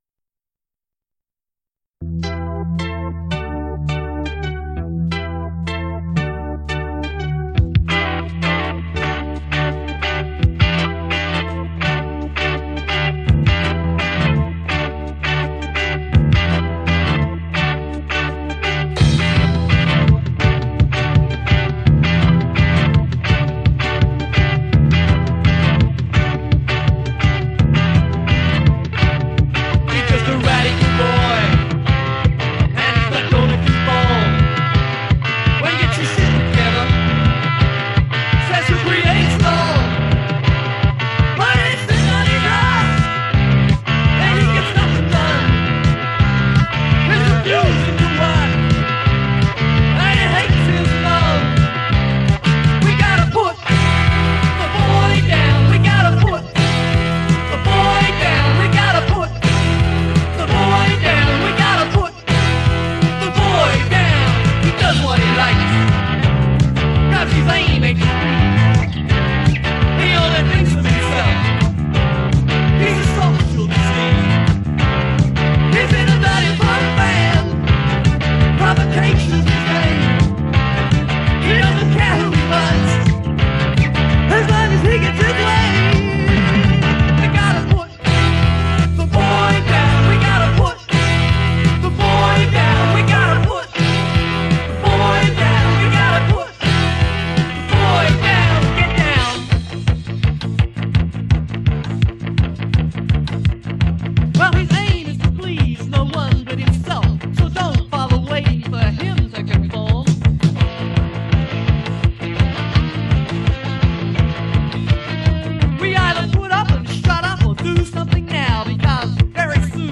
lead guitar and backing vocals